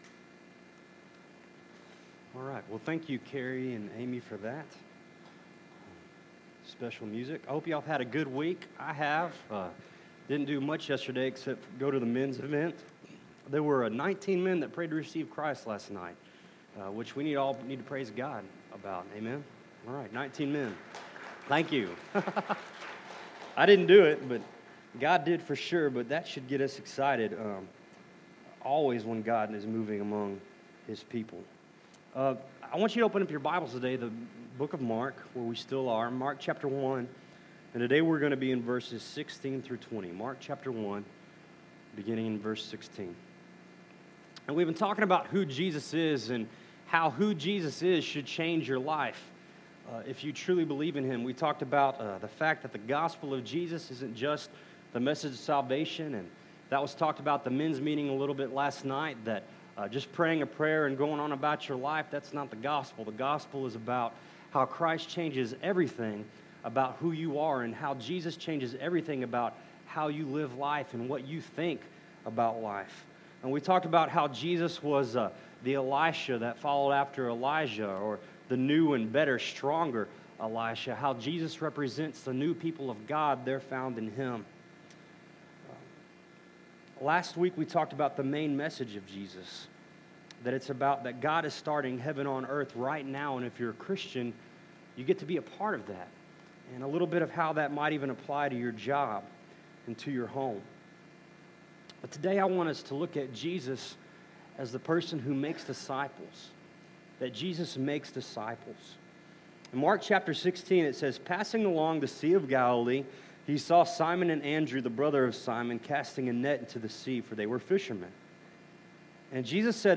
Central Baptist Church of Itasca
Service Type: Sunday Morning